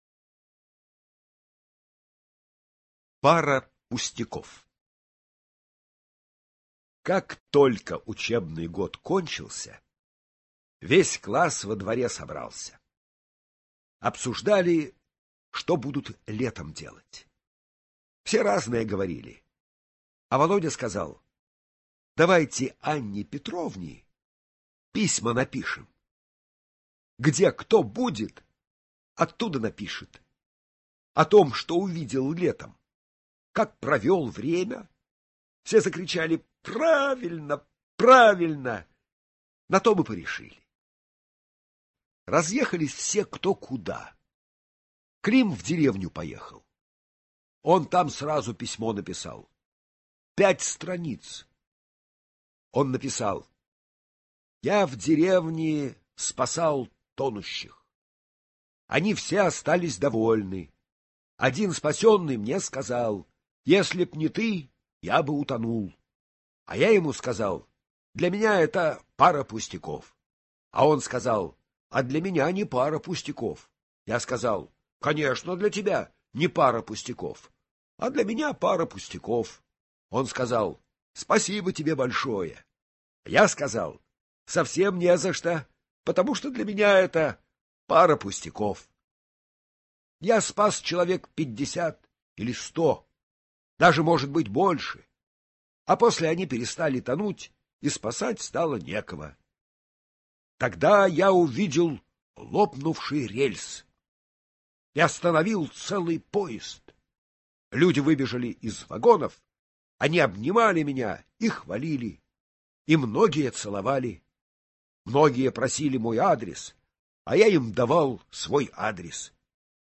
Аудиорассказ «Пара пустяков»